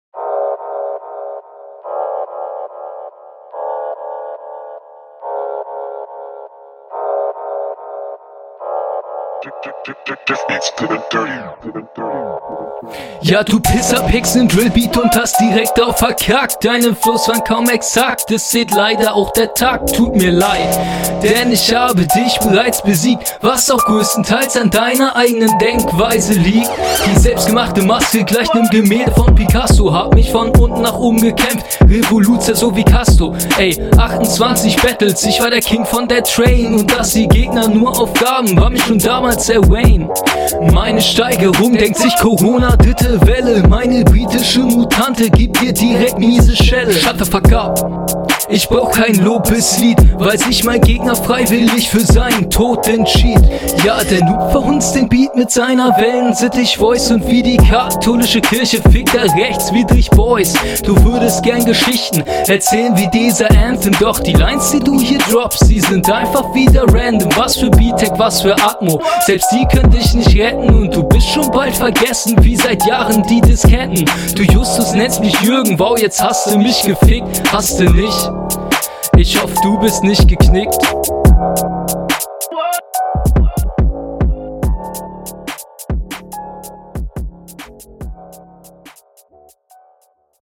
Sound ist auch bei dir gut. Dein Flow ist gleich auf mit deinem Gegner.